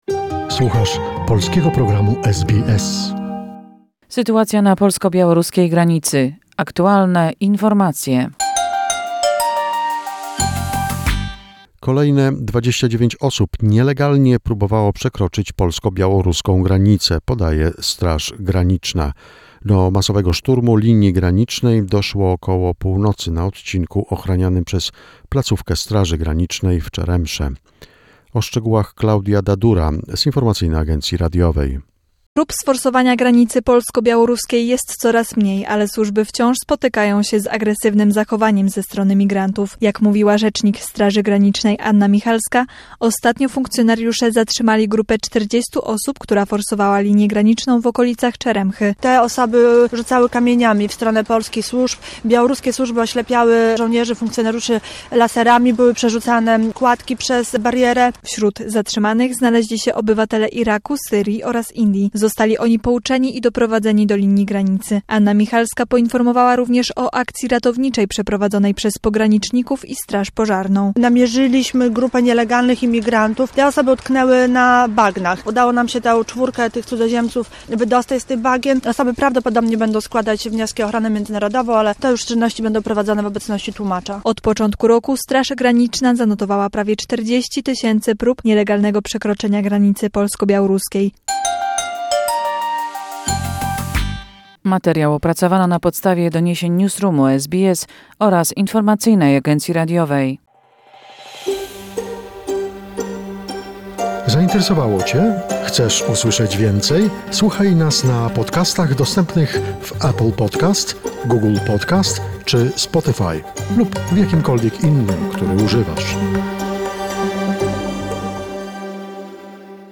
The most recent information and events regarding the Poland-Belarus situation, a short report prepared by SBS Polish.